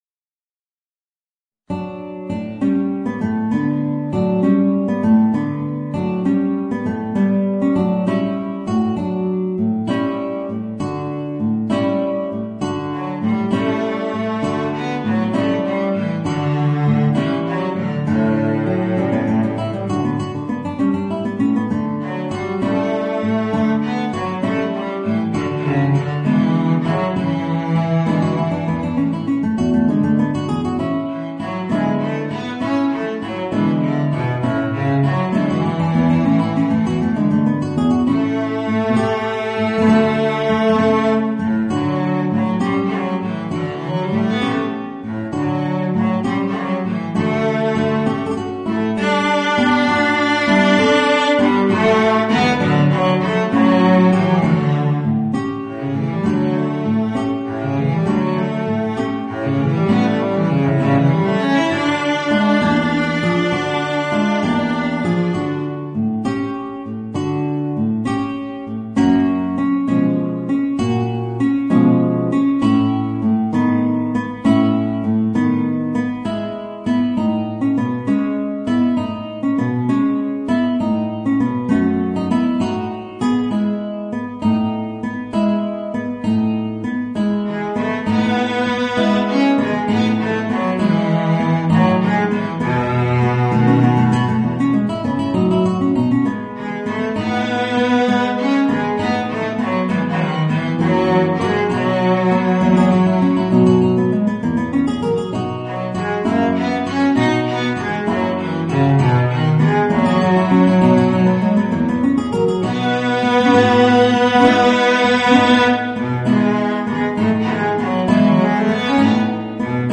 Voicing: Guitar and Violoncello